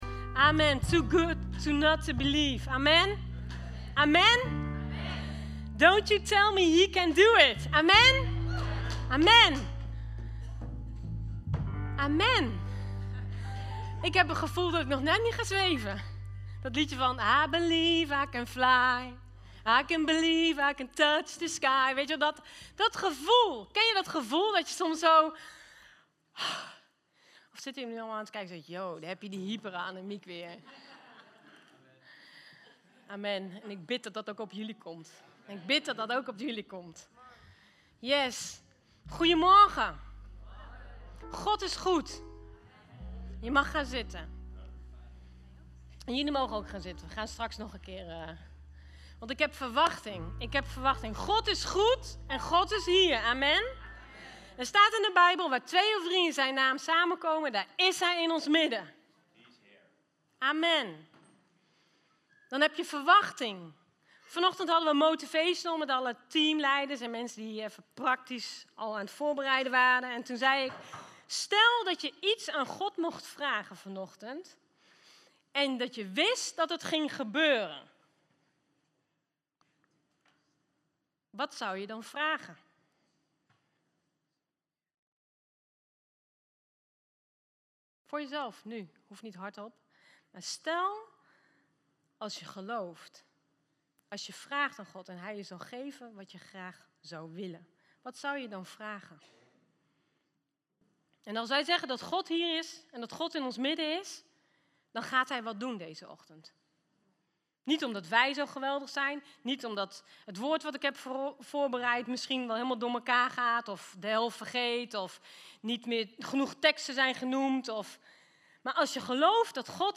Luister hier de preek Download hier het audiobestand.